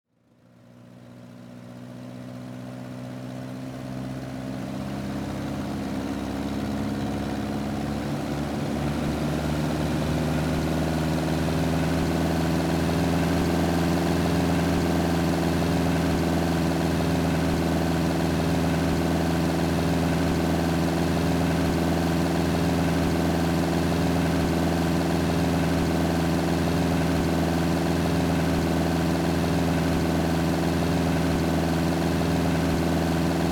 Download Free Aircraft Sound Effects | Gfx Sounds
Light-aircraft-ready-to-take-off-engine.mp3